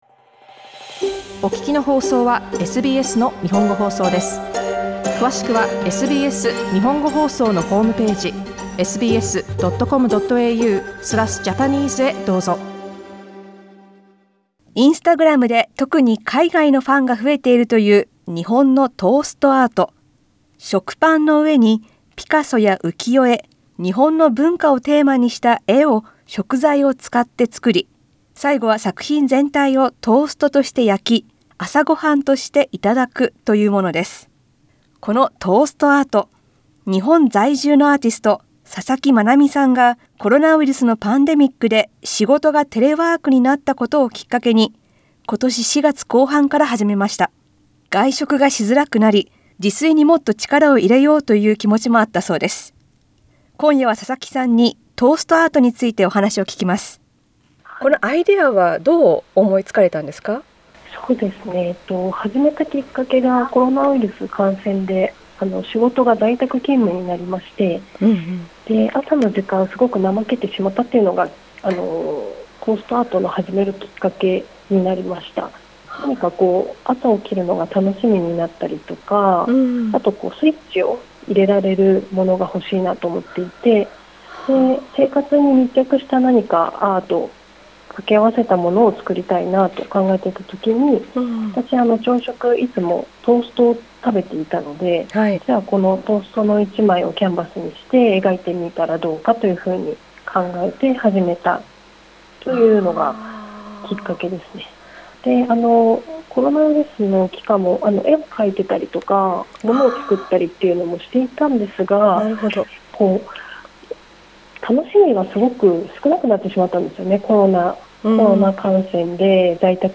インタビューではトーストアートを始めたきっかけや、こだわり、今後計画していることをなどを聞きました。